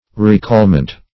\Re*call"ment\ (r[-e]*k[add]l"ment)